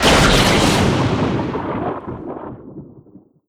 ENEMY_DEMONWALL_THUNDER.ogg